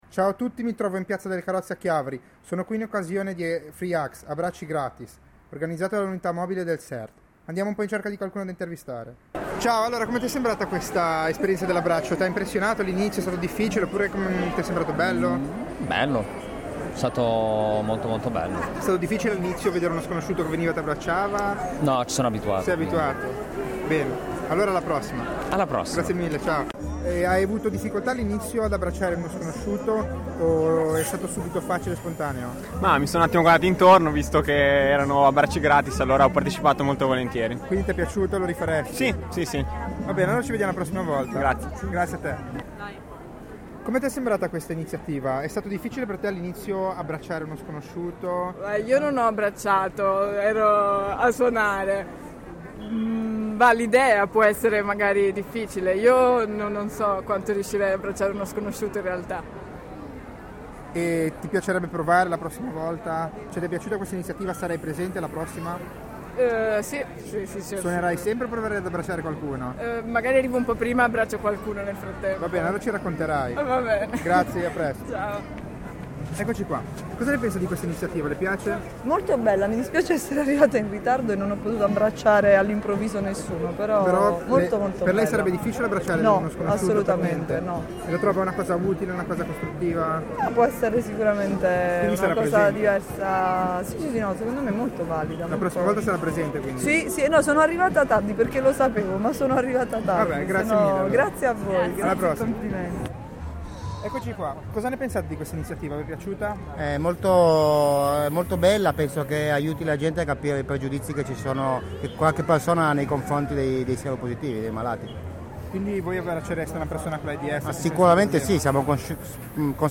Intervista a Partecipanti al Flash Mob per la giornata mondiale della prevenzione all'aids
Intervista svolta durante il flash mob per la giornata mondiale della prevenzione all'aids a Chiavari il 3 dicembre 2011.